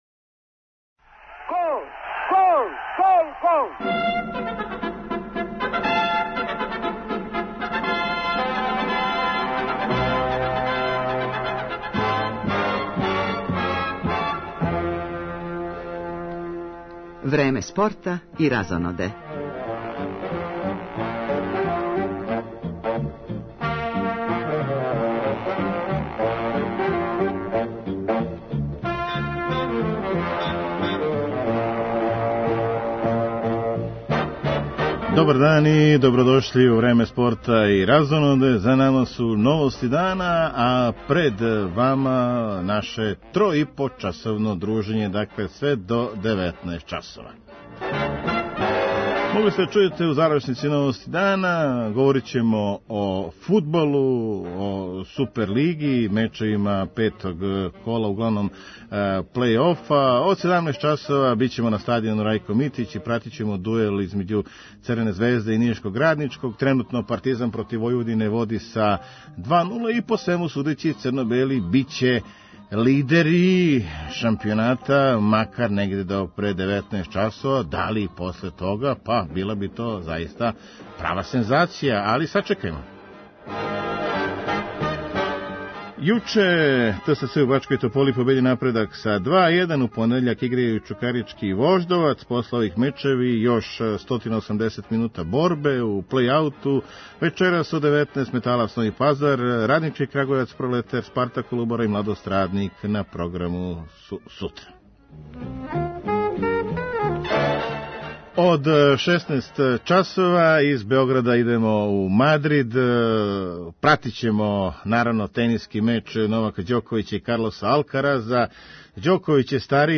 Пратимо и завршницу фубалског емча између Партизана и Војводине који почиње у 14 сати, када и дуел Ђоковића и Алкараза, а од 17 часова смо на стадиону Рајко Митић где играју Црвена звезда и Раднички из Ниша.